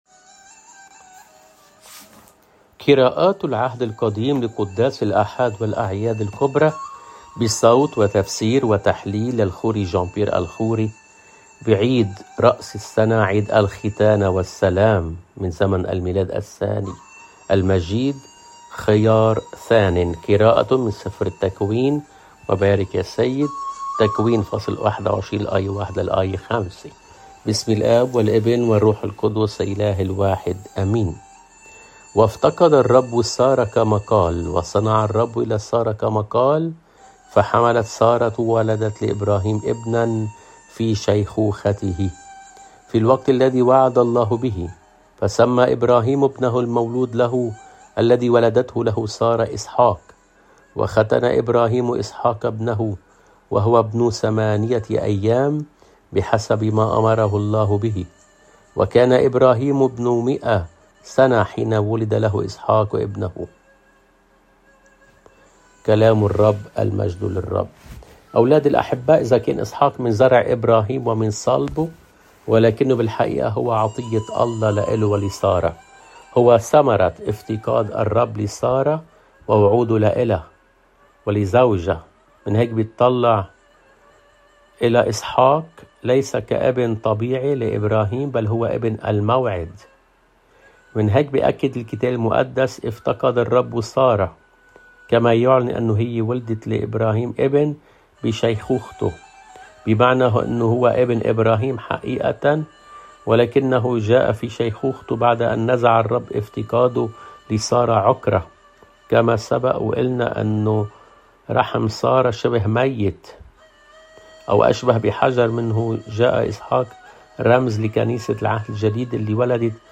قراءة من العهد القديم